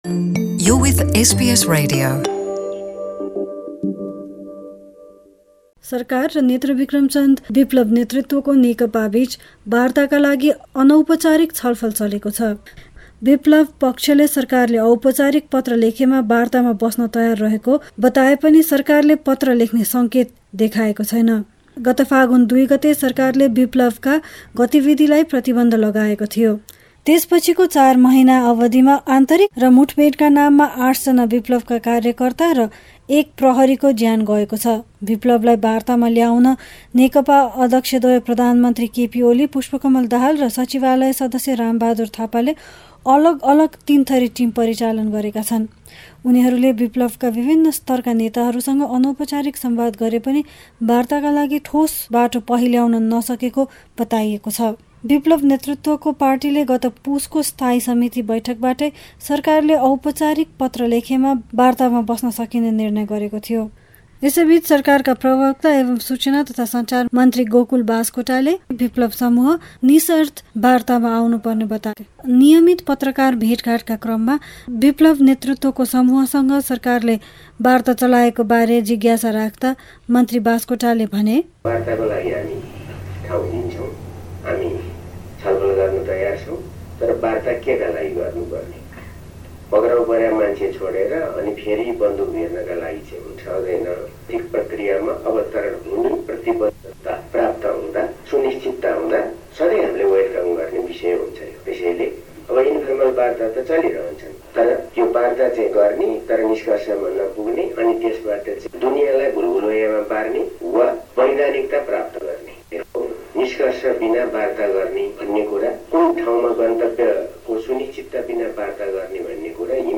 नेपालबाट कमाउने उद्देश्यका लागि बाहिरिने क्रम निरन्तर रहि रहँदा, देश को अर्थतन्त्रले खर्बौ रुपैयाँ रेमिटेन्सको रुपमा भित्राएको छ, र साझा बसले थप्ने भयो अरु विधुतिय बसहरु। यसैबिच, गत हफ्ताको बाढी र पहिरो पिडितहरु अझै राहतको पर्खाईमा। गए ७ दिनका प्रमुख नेपाल समाचार।